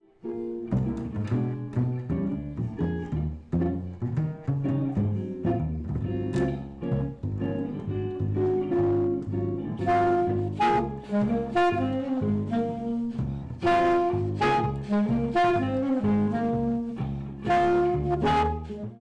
與在「卅四、瞬間電流① - 飽和與slew rate」中用純粹數學方式計算結果聽感一樣，電流驅動能力不足造成的slew rate限制的聽感，並不像是是沒力或聲音大小對比不夠強烈、清楚。而是如災難般的雜音、機械音。
jazz_0.5.wav